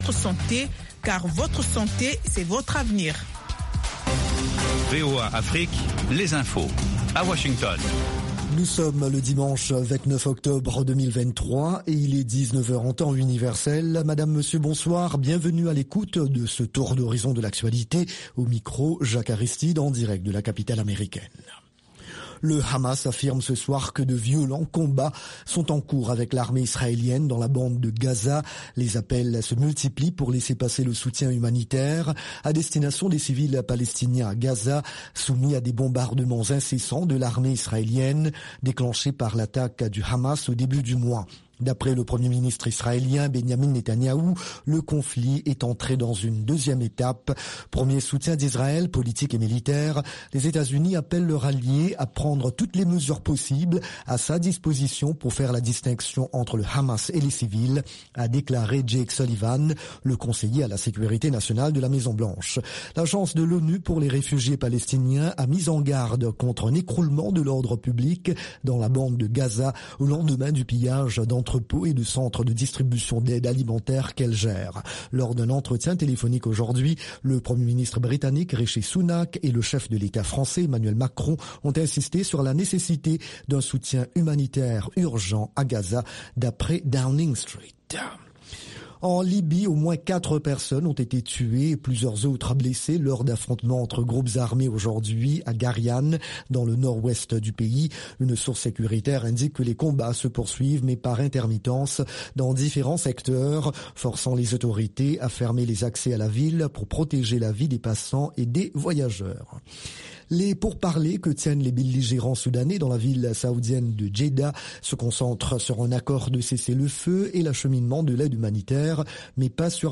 Soul USA - un retour dans les endroits mythiques de la Soul des années 60 et 70.